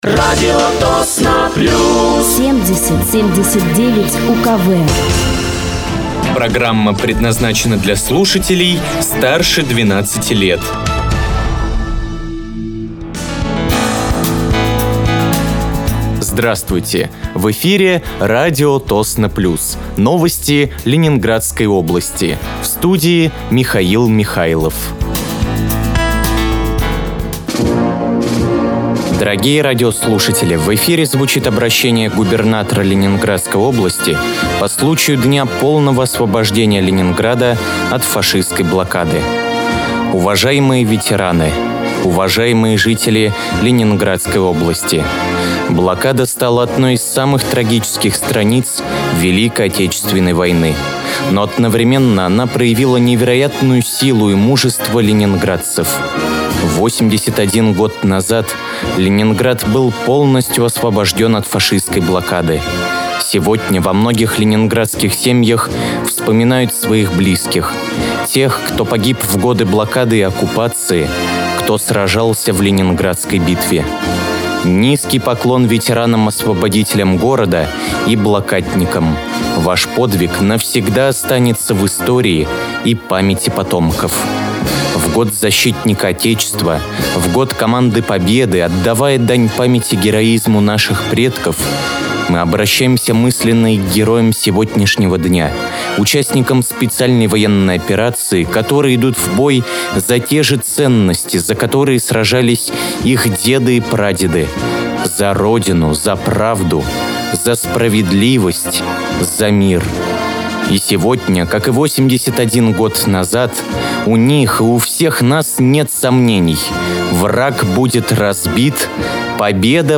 Вы слушаете новости Ленинградской области от 24.01.2025 на радиоканале «Радио Тосно плюс».